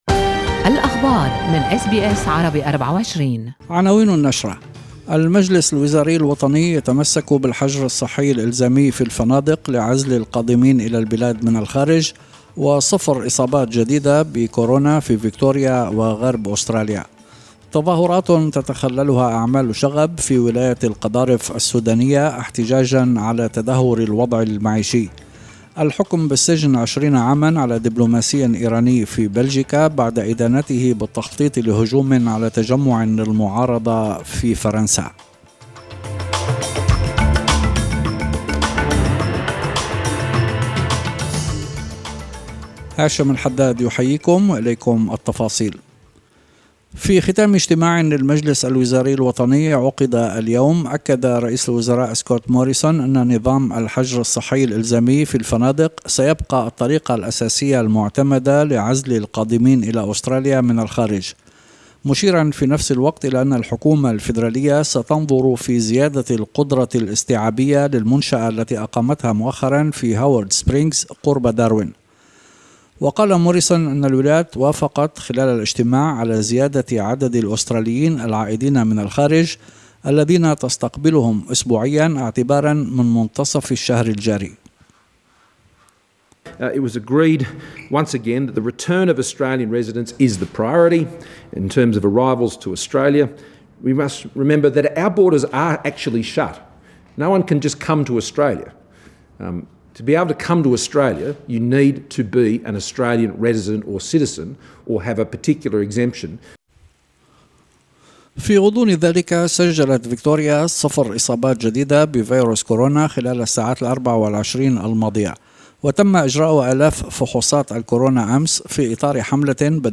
نشرة أخبار المساء 5/2/2021